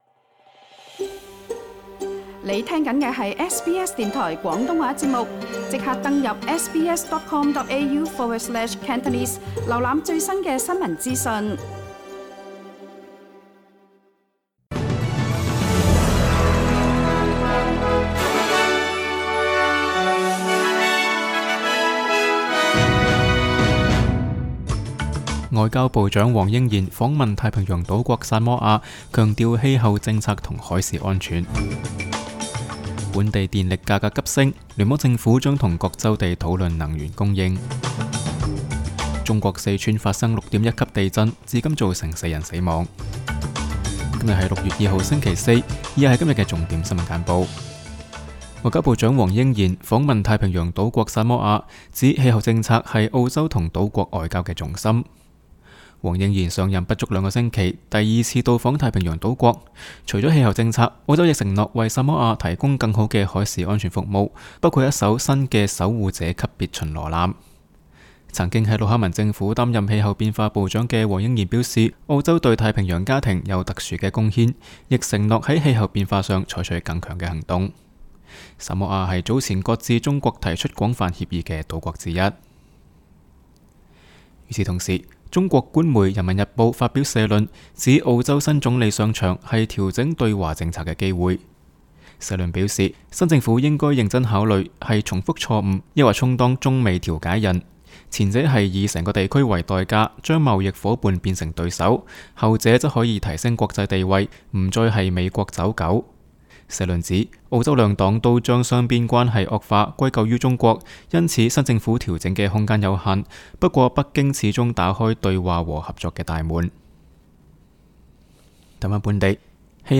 SBS 新闻简报（6月2日）
SBS 廣東話節目新聞簡報 Source: SBS Cantonese